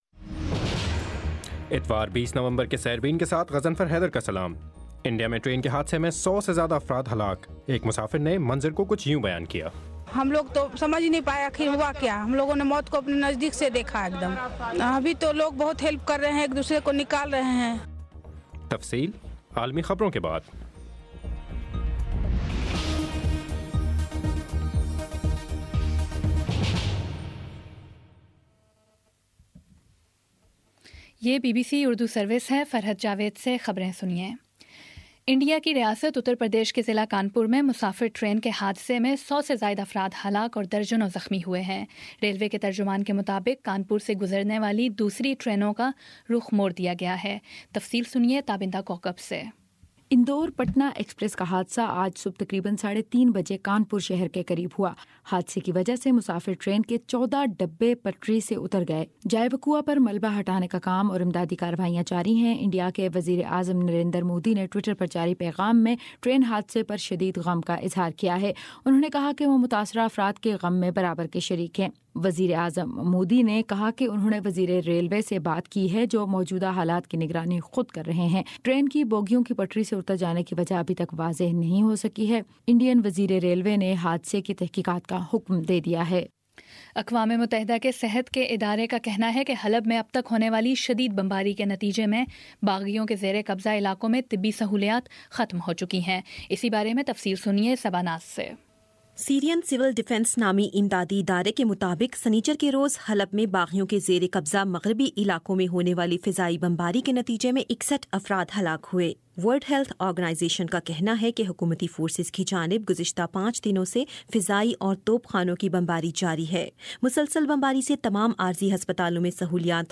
اتوار20 نومبر کا سیربین ریڈیو پروگرام